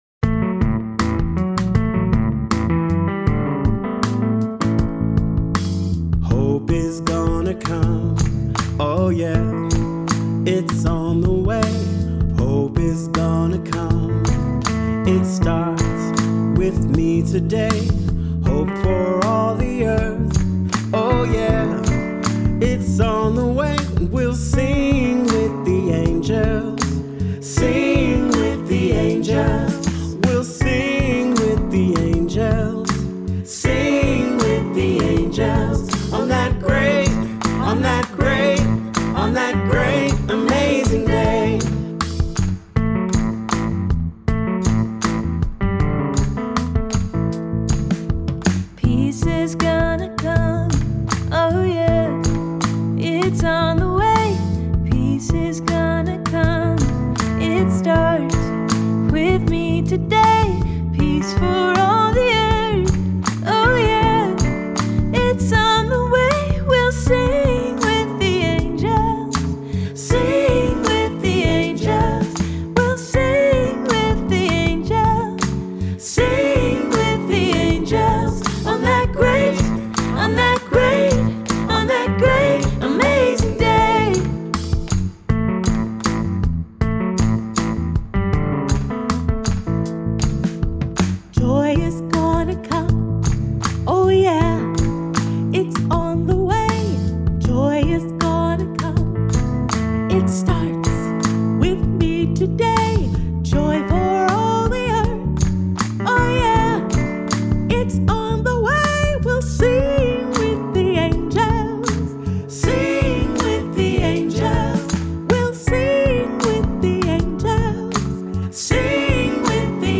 Children’s Songs for Advent and Christmas